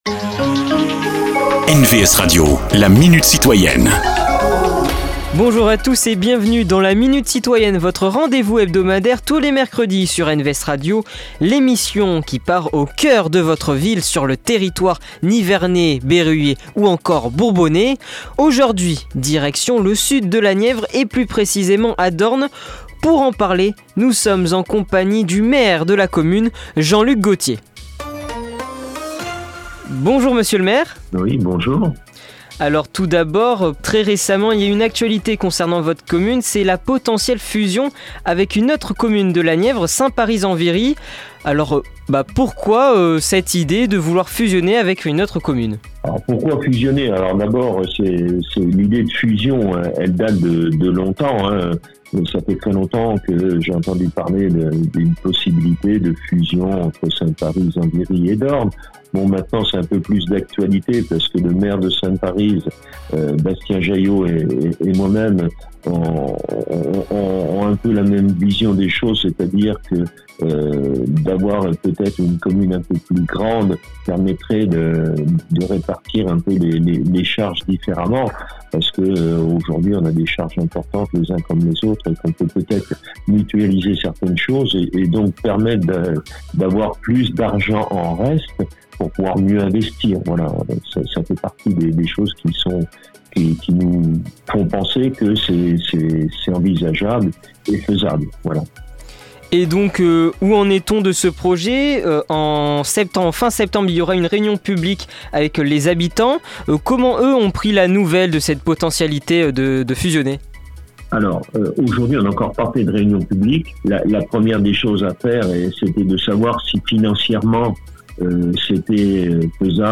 Rencontre avec ceux qui font l’actualité du territoire.
Cette semaine, Jean-Luc Gauthier, maire de Dornes.